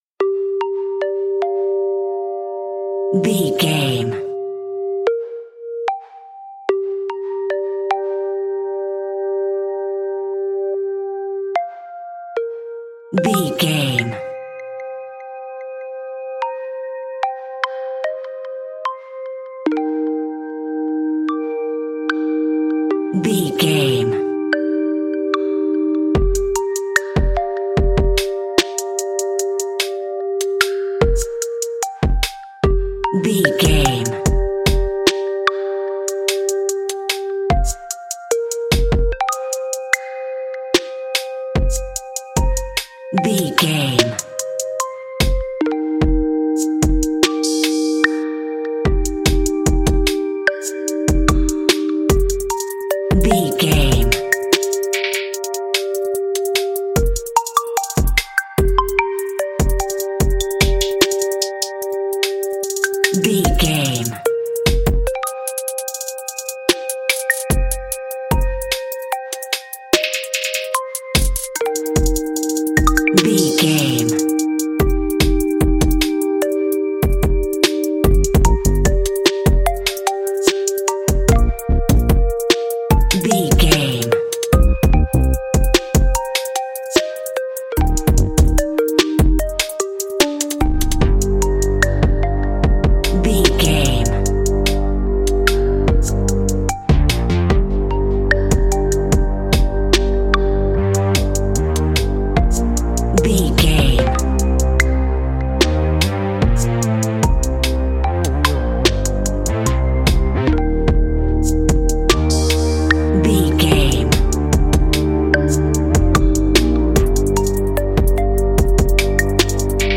In-crescendo
Uplifting
Ionian/Major